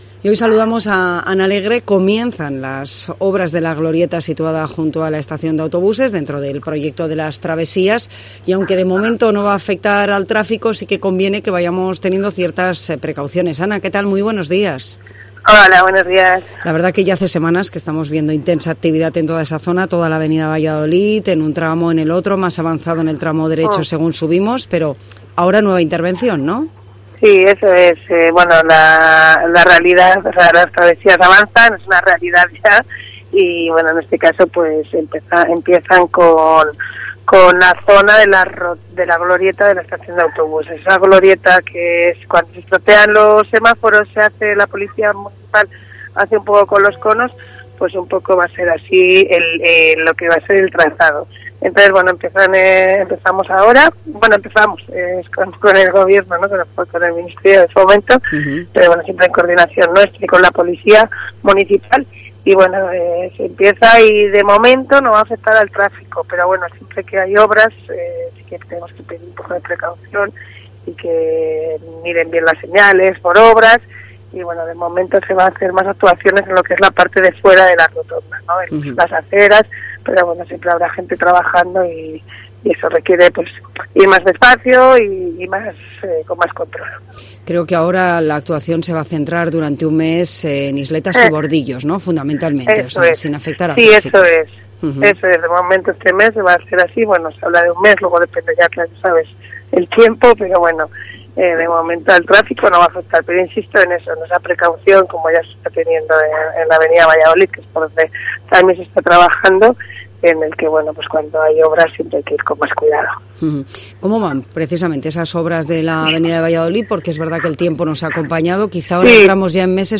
AUDIO: La concejal Ana Alegre nos informa del inicio de obras en la glorieta de la Avenida de Valladolid de Soria